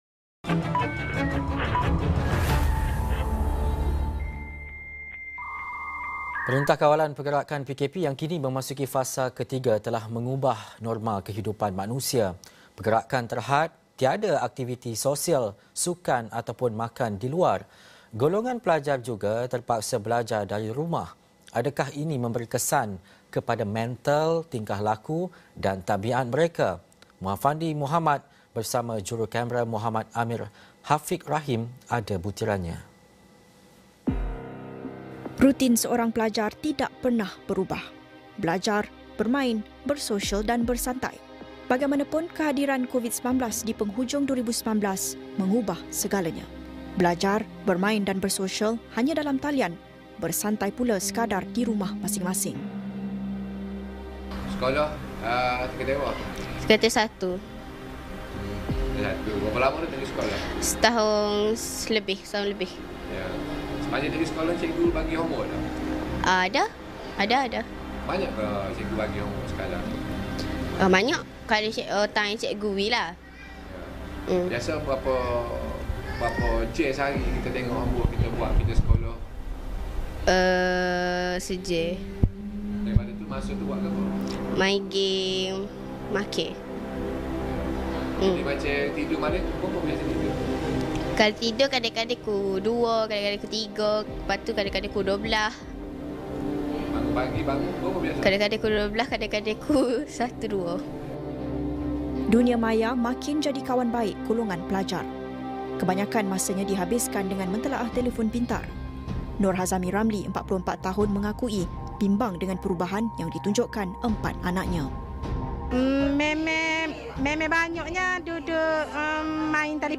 Laporan Khas | PdPR: Bagaimana untuk bantu tangani emosi pelajar